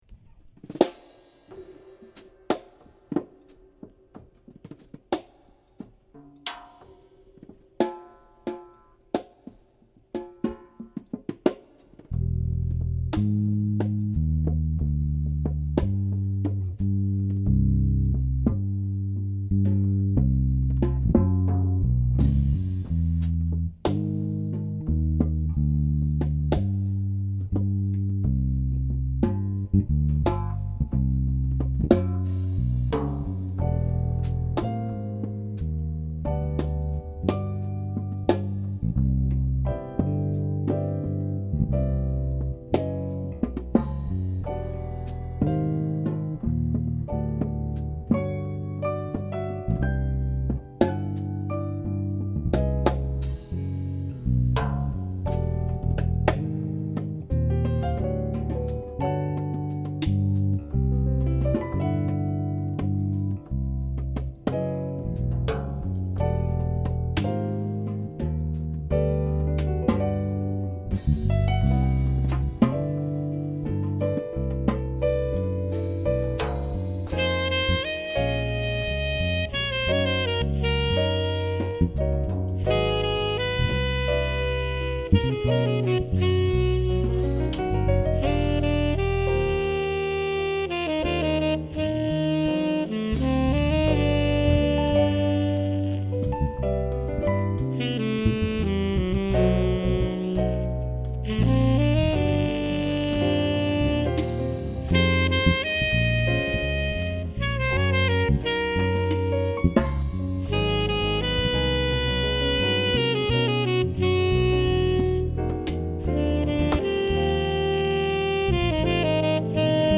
sax
piano
bass
drum set